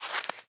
Minecraft Version Minecraft Version latest Latest Release | Latest Snapshot latest / assets / minecraft / sounds / block / powder_snow / step2.ogg Compare With Compare With Latest Release | Latest Snapshot